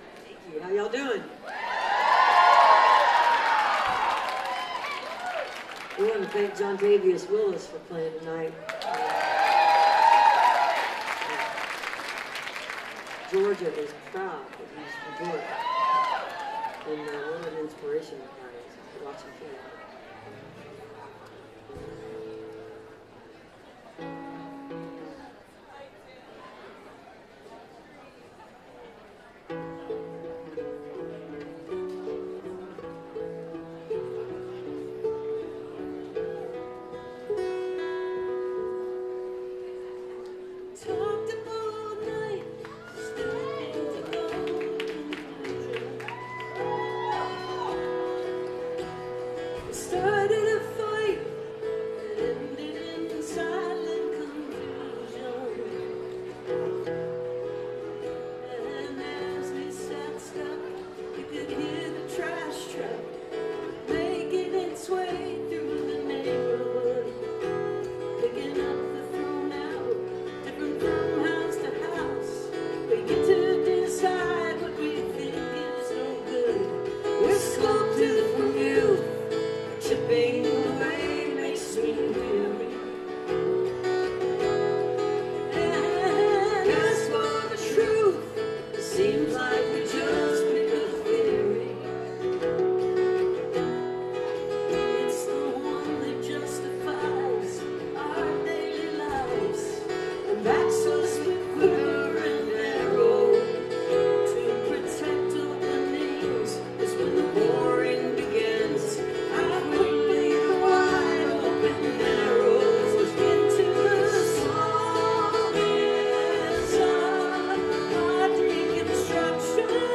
live stream on twitch